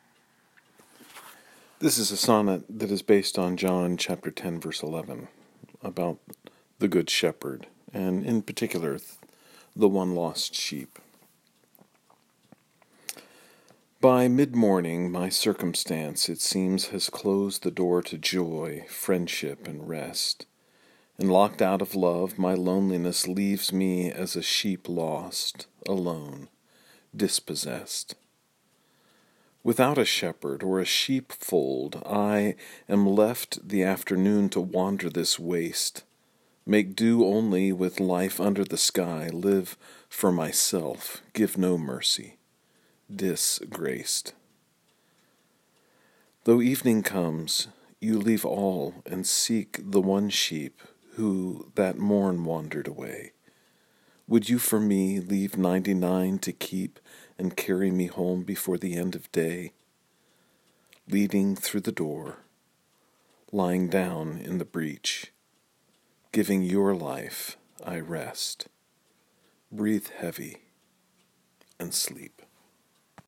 If it is helpful you may listen to me read the sonnet via the player below.